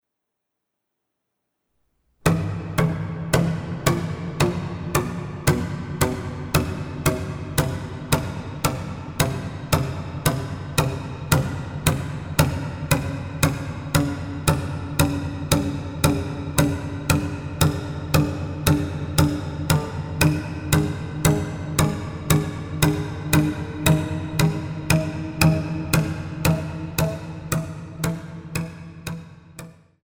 Violoncello und Arrangements